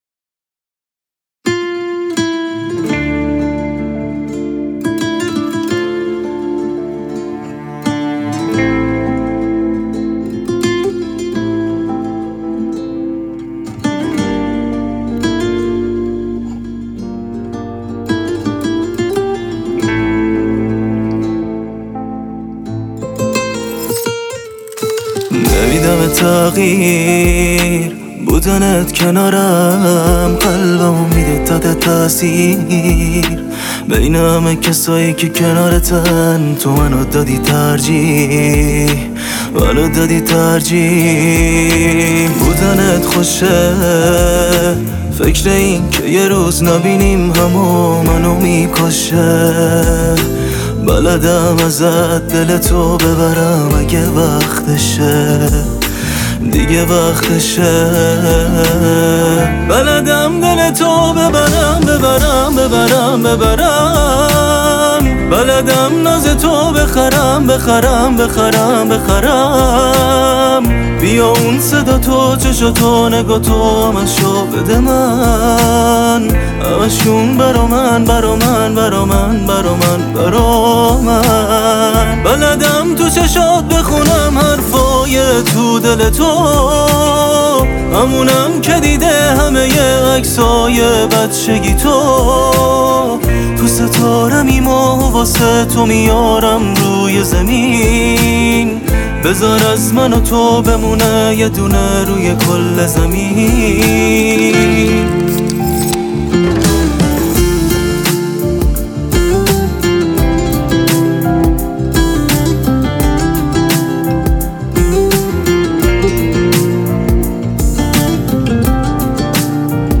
موزیک عاشقانه پائیز موزیک احساسی
خواننده جوان